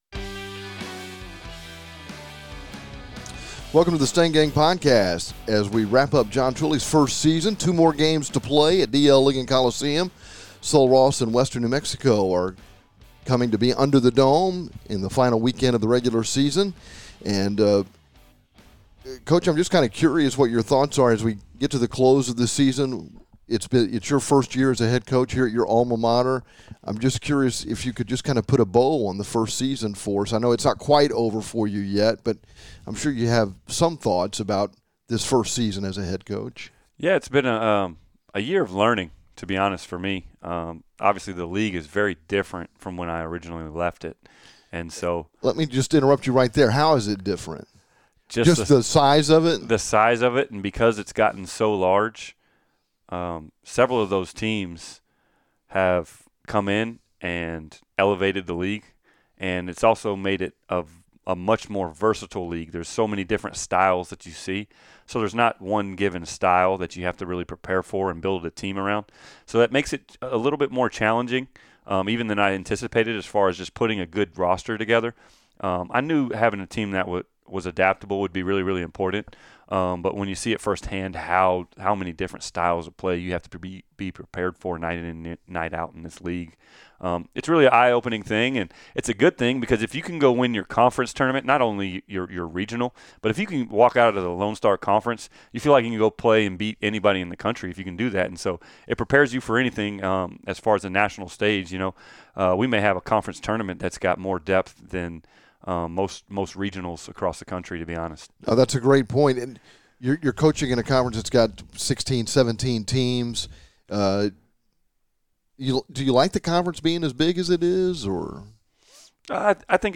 A candid conversation